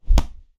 Close Combat Attack Sound 19.wav